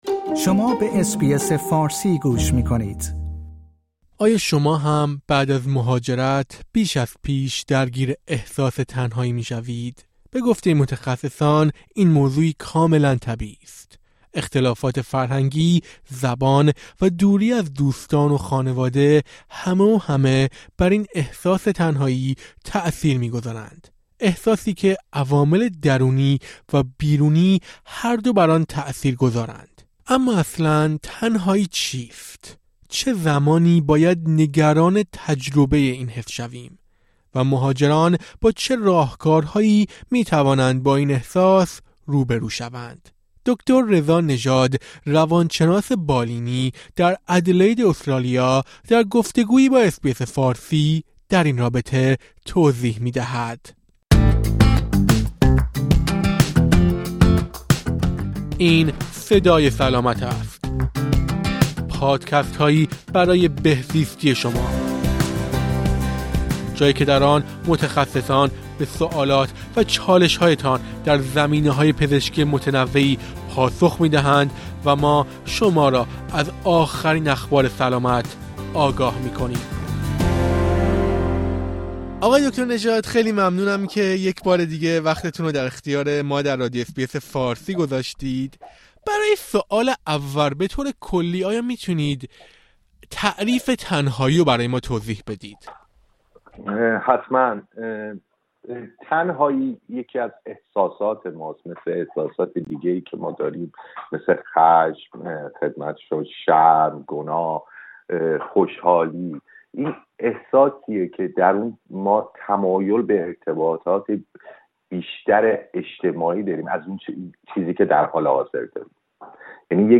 روان‌شناس بالینی در گفت‌وگو با اس‌بی‌اس فارسی در این رابطه توضیح می‌دهد.